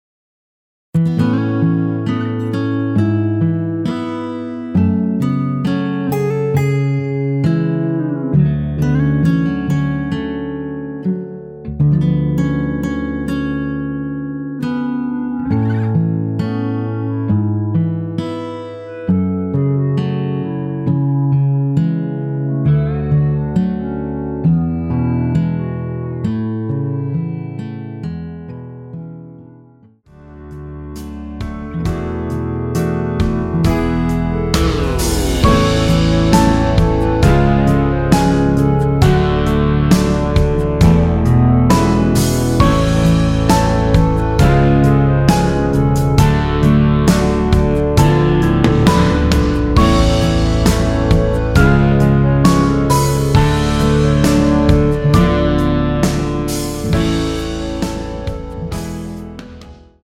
원키에서(-3) 내린 멜로디 포함된 MR 입니다.(미리듣기 확인)
앞부분30초, 뒷부분30초씩 편집해서 올려 드리고 있습니다.
중간에 음이 끈어지고 다시 나오는 이유는